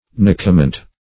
Search Result for " nucament" : The Collaborative International Dictionary of English v.0.48: Nucament \Nu"ca*ment\, n. [L. nucamenta fir cones, fr. nux, nucis, a nut.]